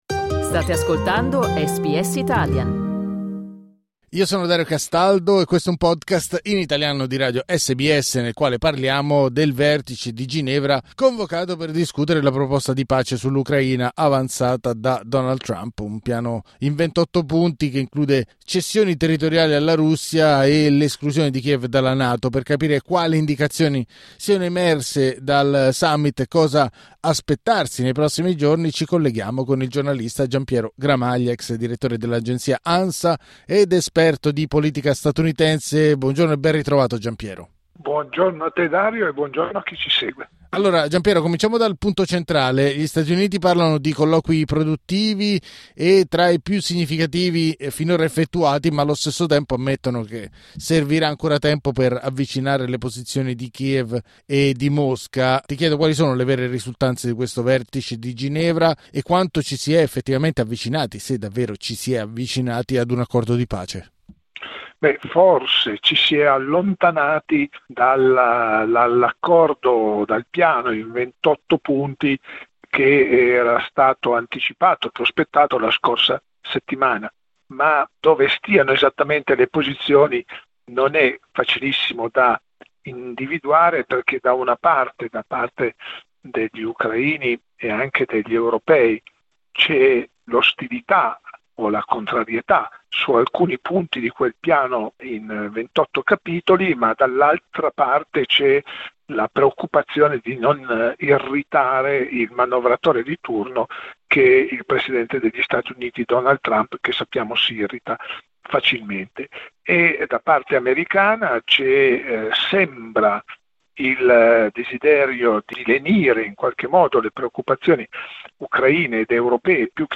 Clicca sul tasto "play" per ascoltare l'analisi del giornalista